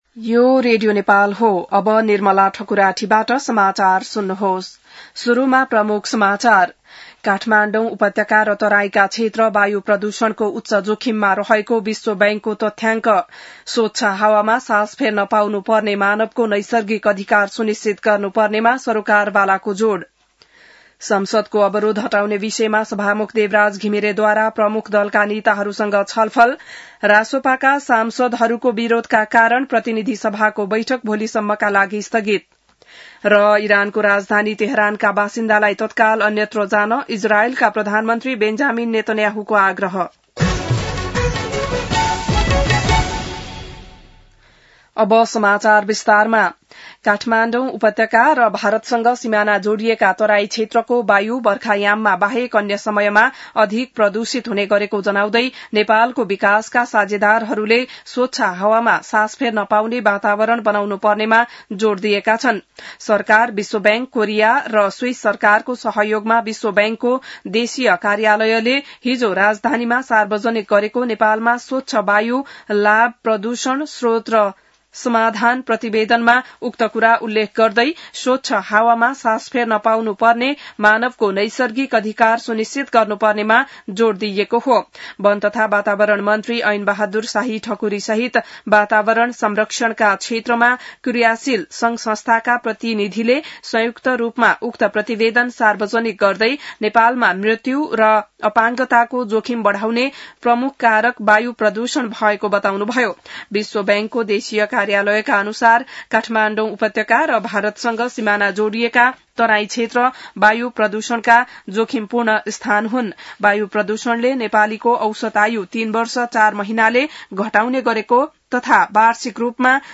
बिहान ९ बजेको नेपाली समाचार : ४ असार , २०८२